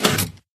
piston_out.mp3